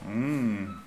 hum-delicieux_02.wav